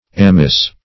Amyss \Am"yss\ ([a^]m"[i^]s), n. Same as Amice , a hood or cape.